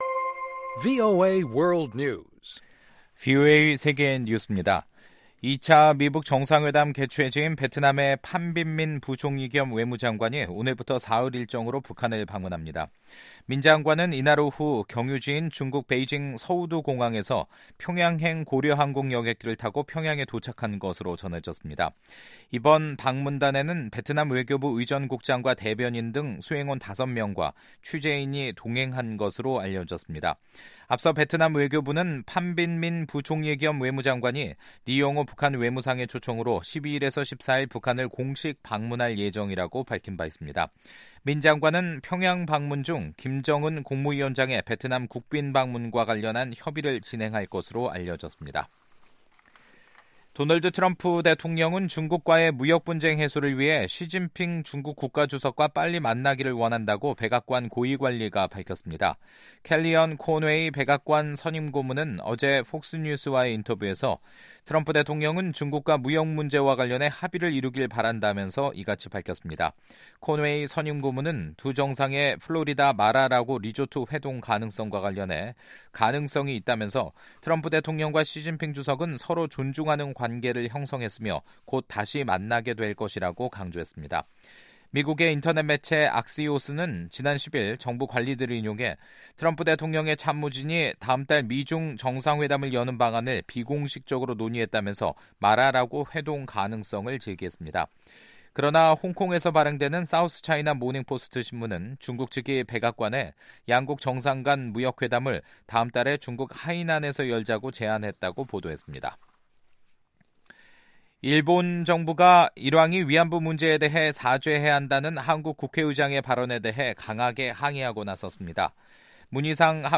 VOA 한국어 간판 뉴스 프로그램 '뉴스 투데이', 2019년 2월 12일 3부 방송입니다. 2차 미북 정상회담을 앞두고 북한 수용소의 전면 철폐를 촉구하는 결의안이 미 하원에서 발의 됐습니다. 미 국무부에서 민주주의, 인권, 노동담당 차관보를 역임했던 톰 말리노스키 하원의원이 2차 미-북 정상회담에서 북한 인권문제를 의제로 다뤄야 한다고 밝혔습니다.